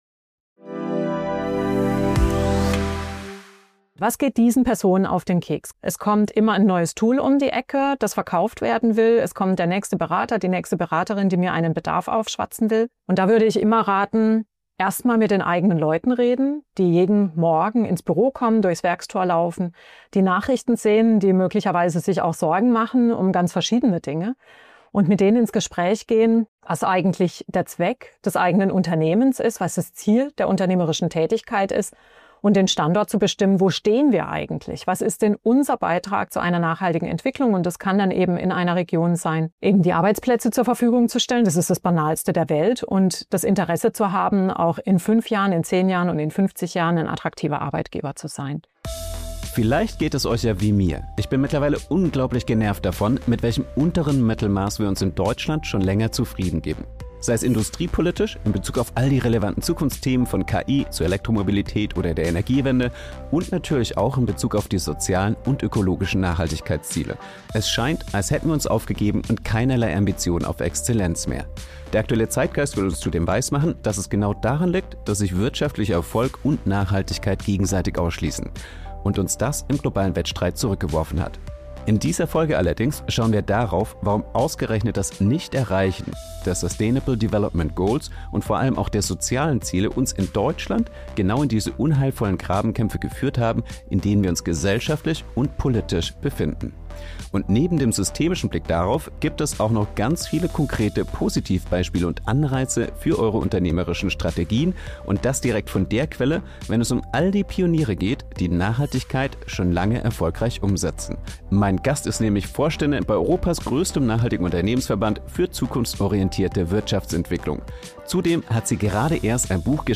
Freue Dich auf eine anspruchsvolle Diskussion, die vermeintliche Widersprüche auflöst - rund um wirtschaftliches Wachstum und unsere Nachhaltigkeitsziele.